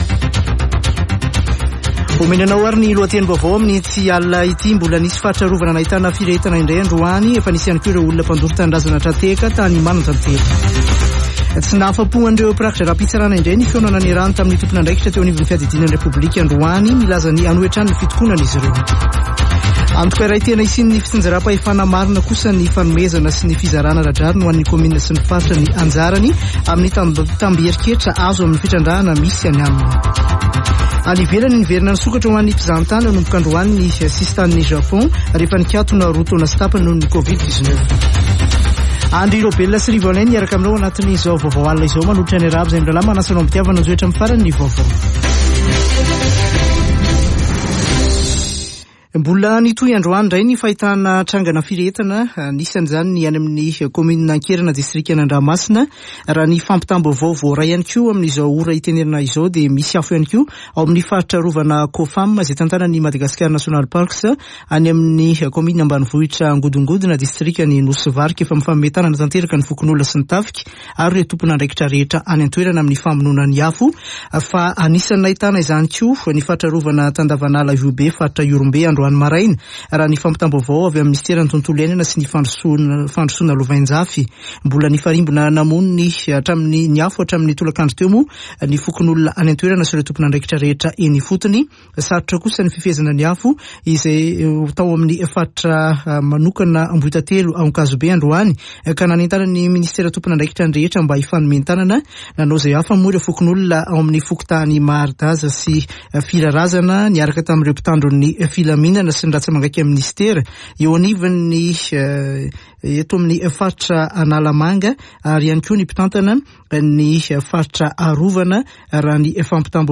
[Vaovao hariva] Talata 11 ôktôbra 2022